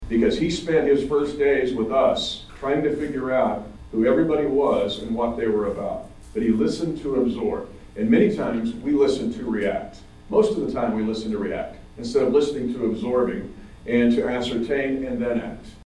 Holthus noted in his remarks the “secret sauce” to the Chiefs recent winning success, crediting Coach Reid’s ability to be an effective listener, listening to both absorb and to ascertain.